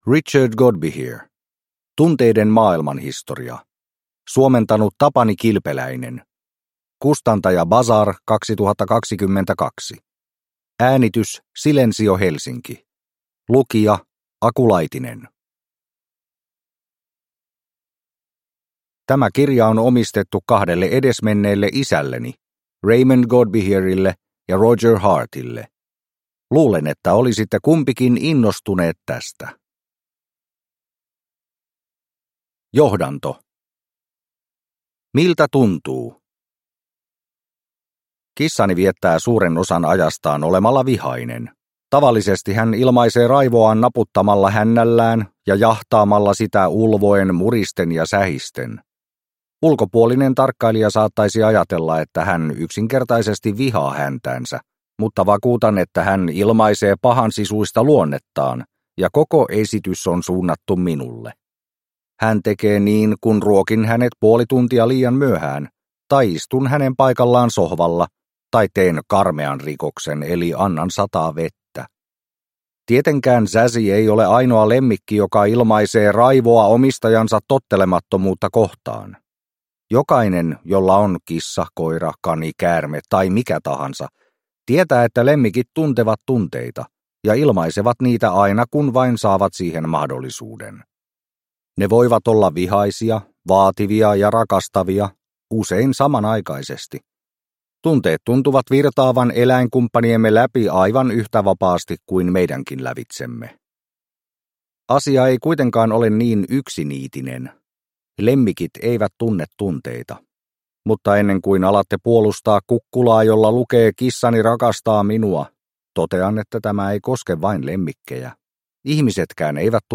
Tunteiden maailmanhistoria – Ljudbok – Laddas ner